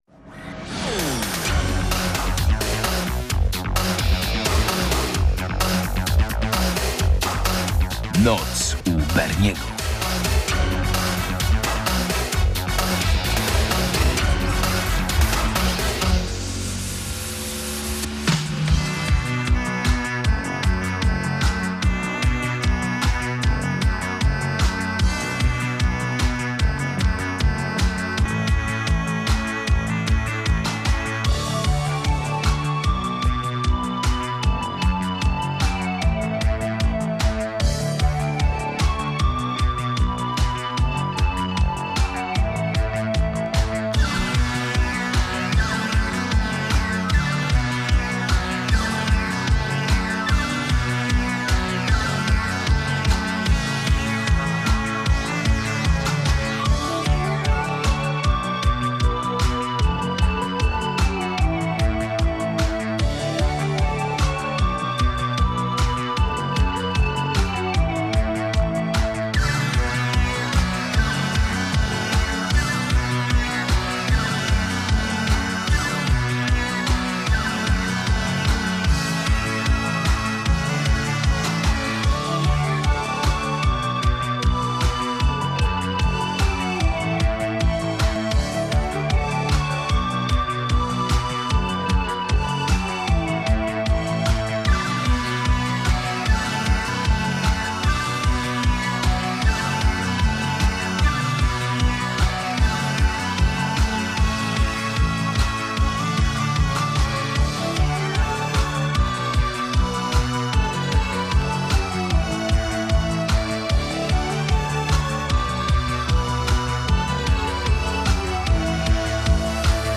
Dominujący gatunek: trochę synth, bardziej pop